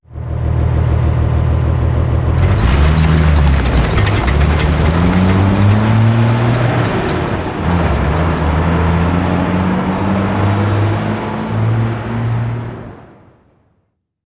Salida de un autobús antiguo
arranque
autobús
Sonidos: Transportes